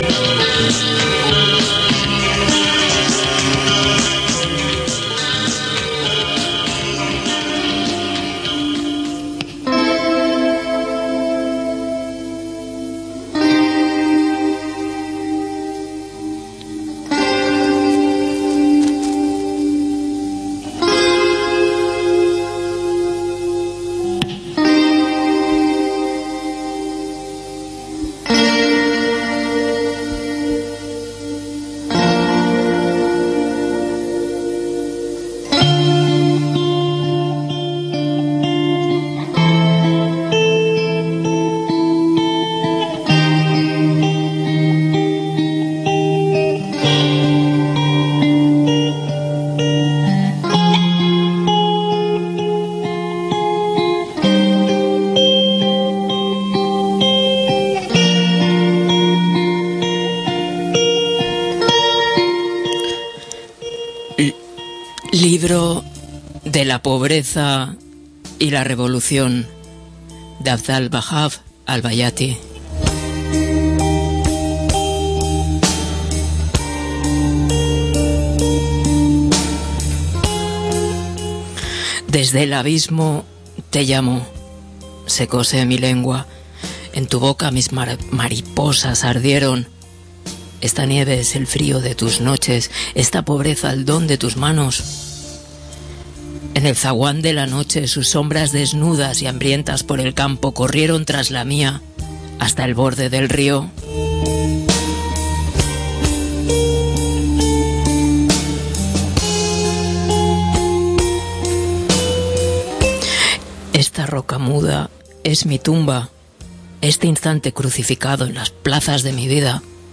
Leemos el relato homónimo, precedido por unos versos manzanescos de Yeats.